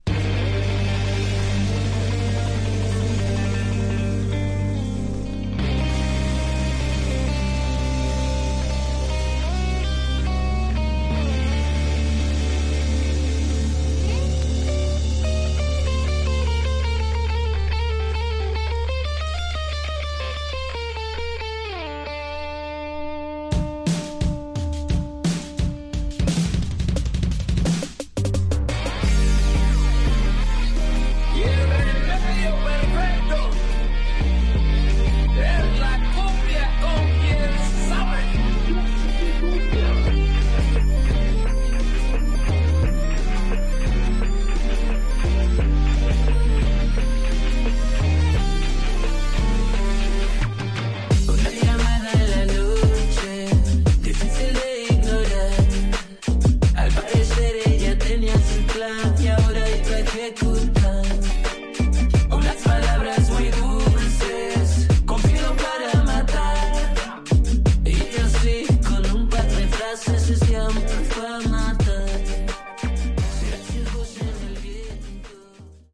Tags: Cumbia
Super bailables: cumbia con raps, rock, housito.